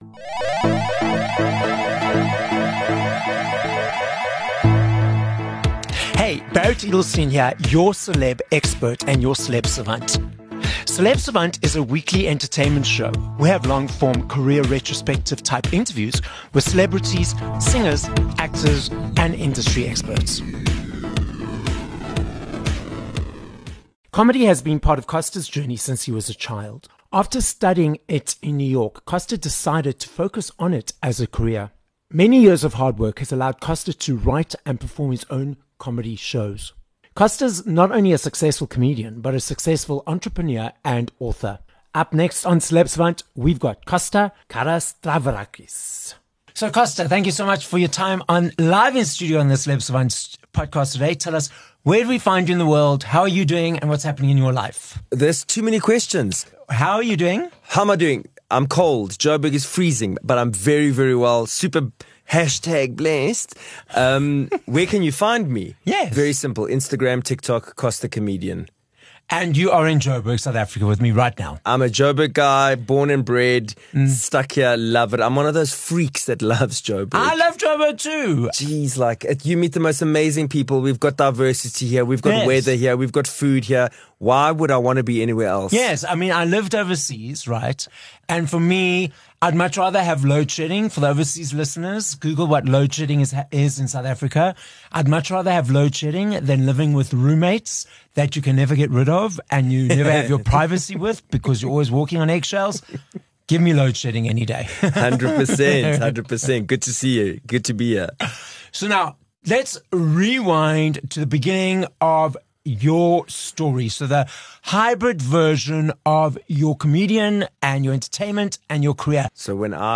Interview
is joined live in studio by comedian